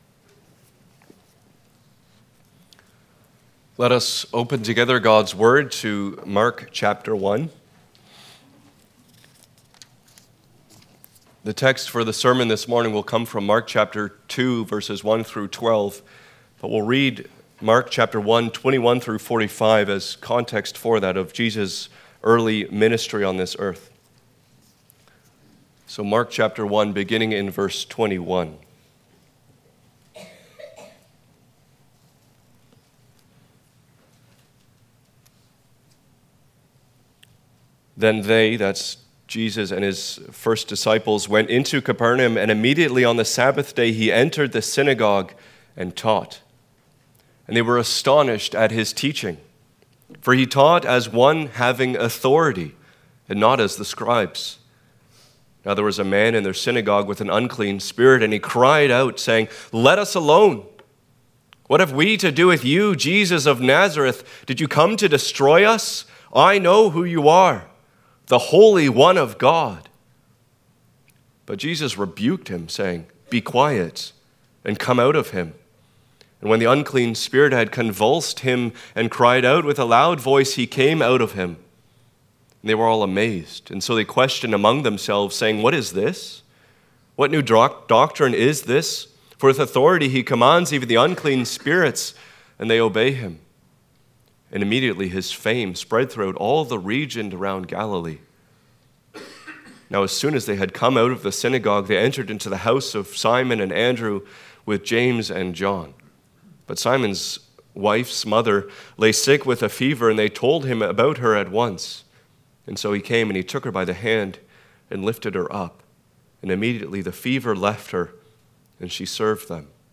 Passage: Mark 1:21-45 Service Type: Sunday Morning